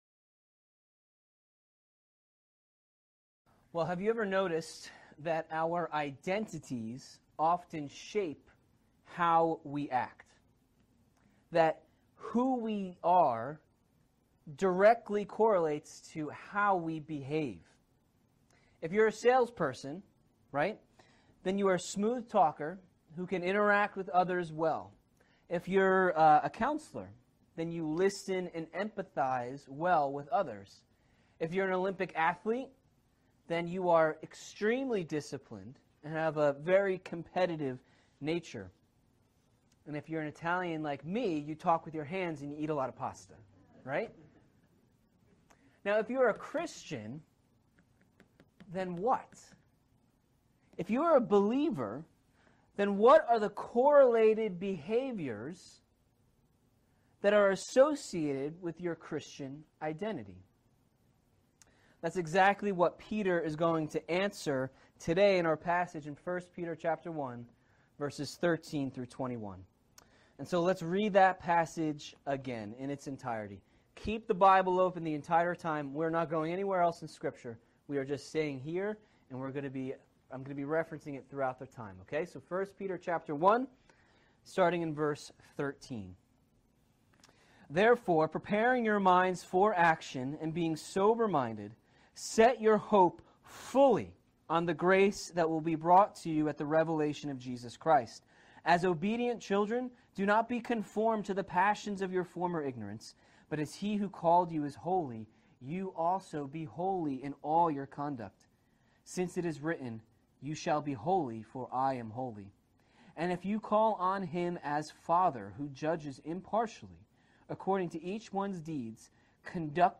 Sermons | Faith Bible Church